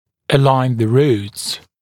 [ə’laɪn ðə ruːts][э’лайн зэ ру:тс]выравнивать корни (зубов)